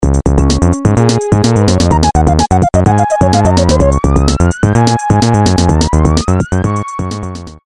Nokia полифония. Мультики